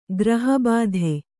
♪ graha bādhe